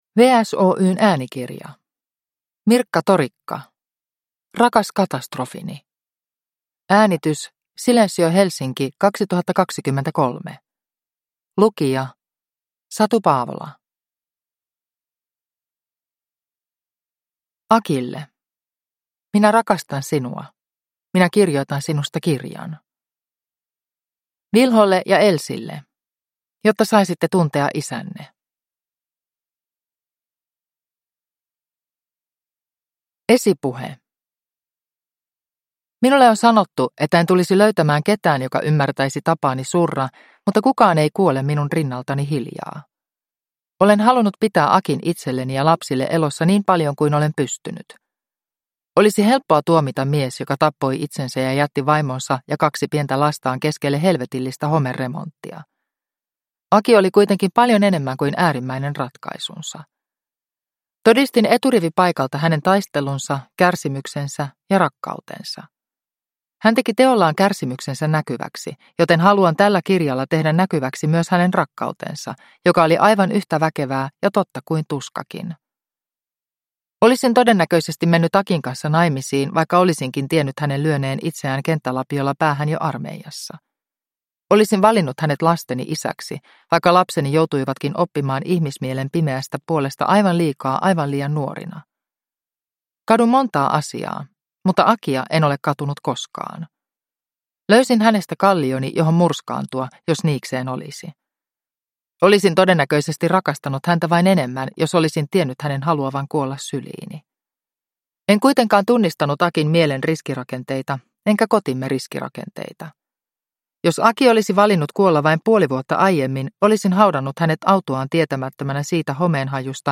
Rakas katastrofini – Ljudbok – Laddas ner